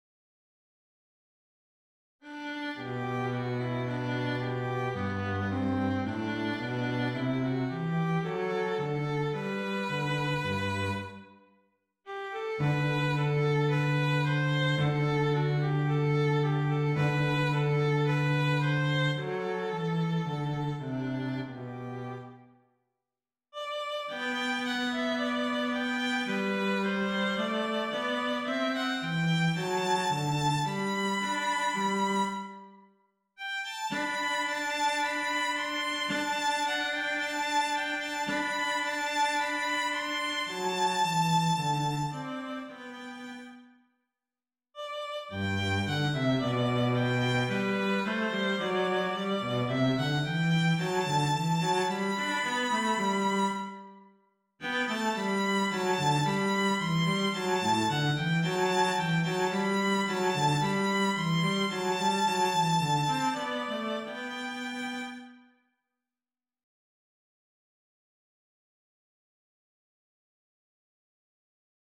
(G)